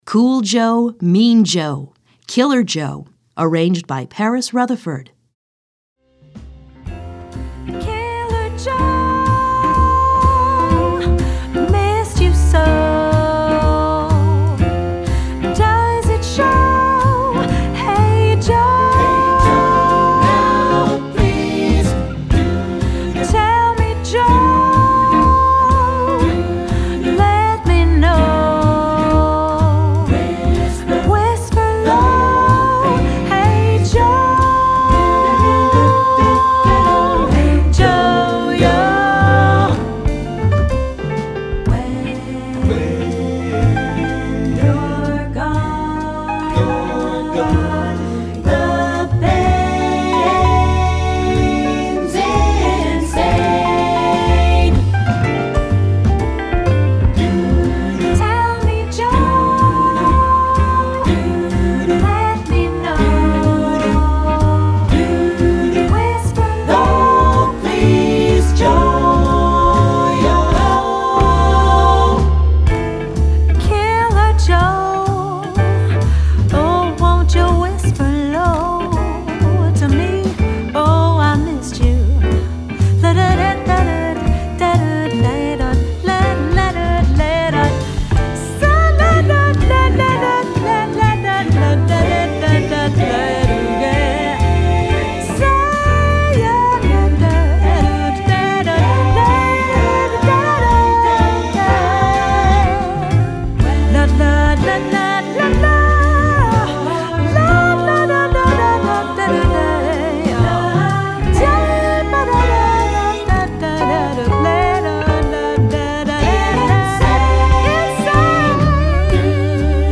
Choral Jazz